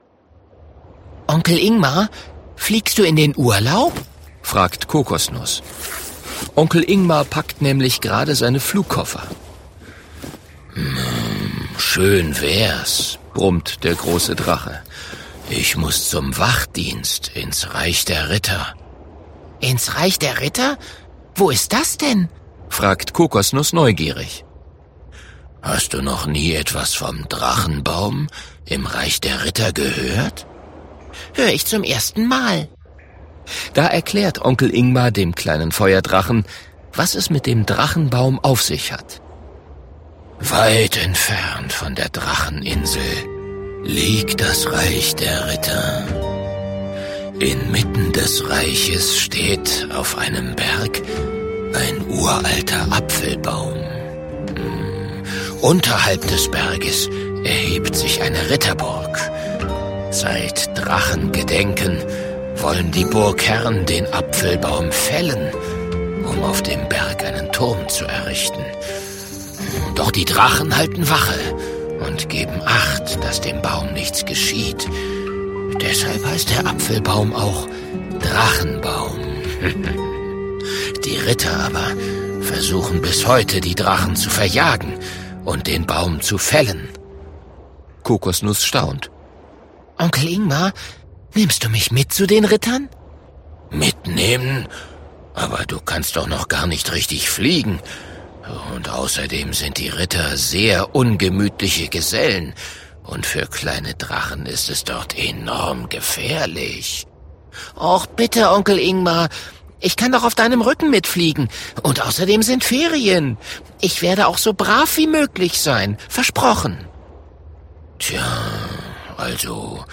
Ungekürzte Lesung, Inszenierte Lesung mit Musik